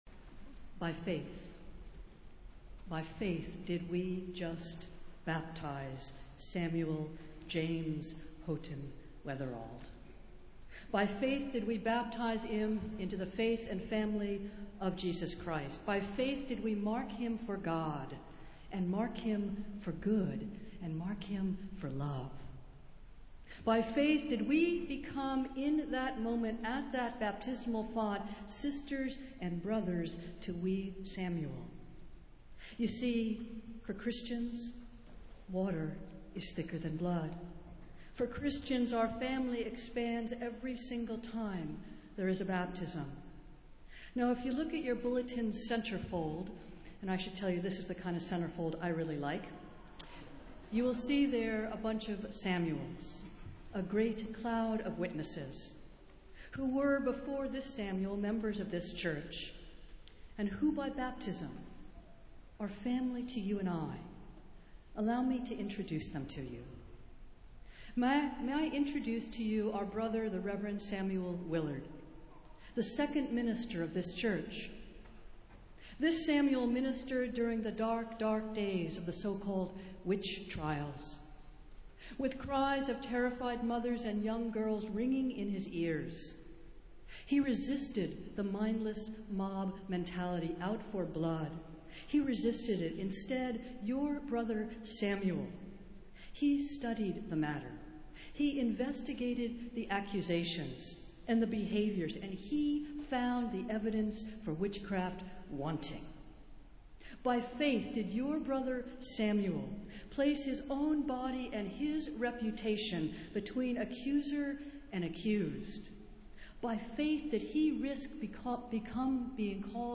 Festival Worship - Sixth Sunday after Pentecost